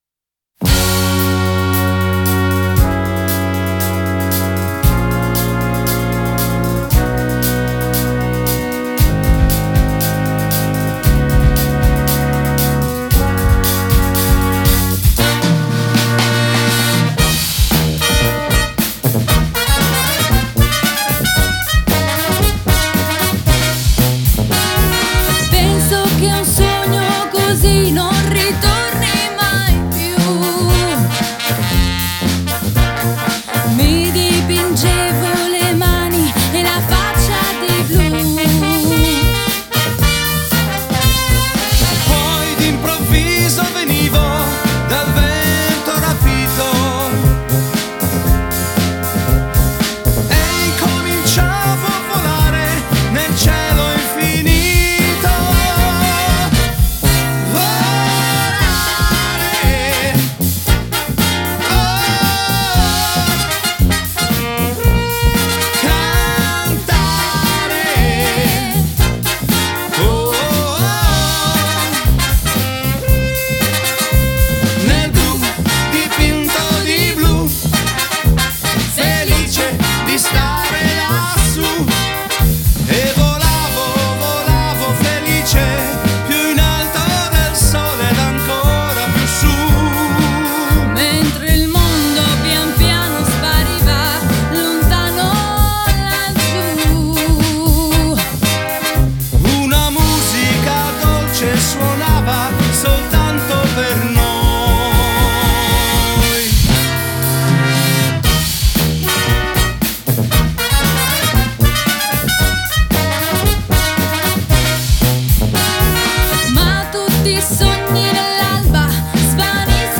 Genre: Swing, Jazz